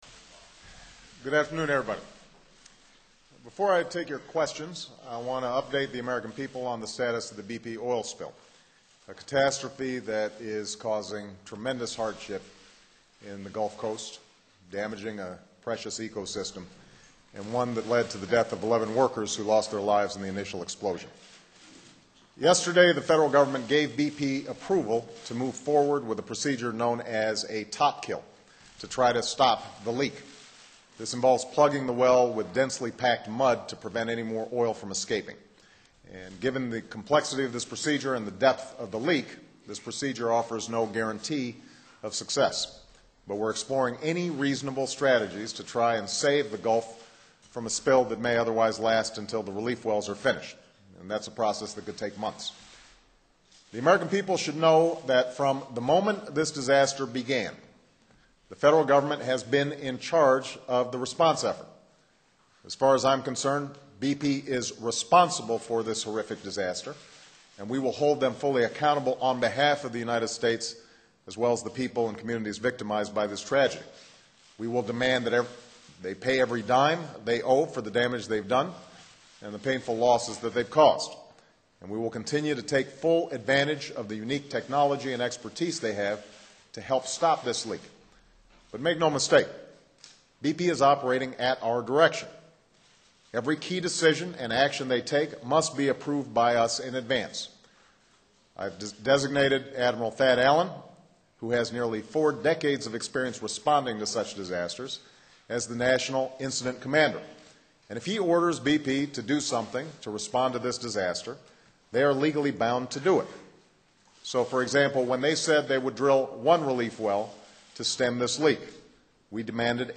President Obama holds his first news conference on the disaster in the Gulf of Mexico.
President Barack Obama faced tough questions about his administration's handling of the deep sea oil leak in the Gulf of Mexico in a White House news conference Thursday, 27 May. It was the president's first news conference since the Deepwater Horizon oil rig exploded and sank in April.